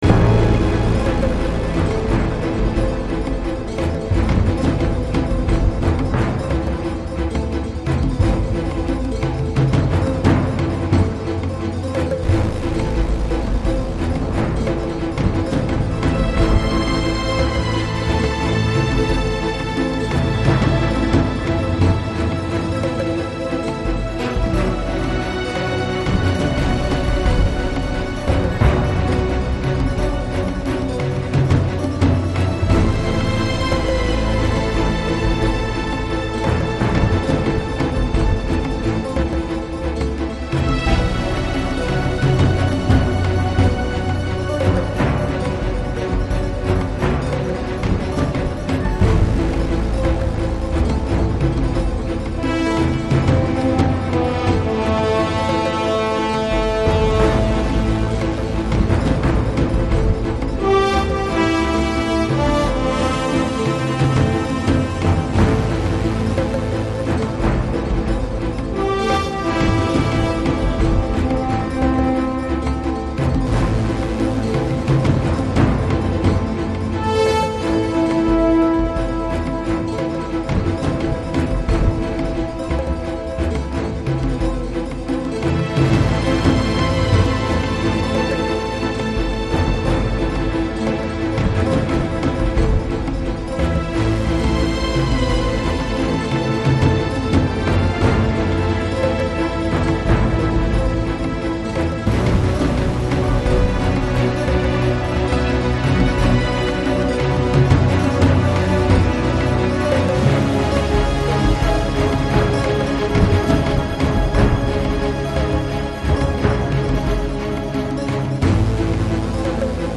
Жанр: New Age Electronic Ambient Classical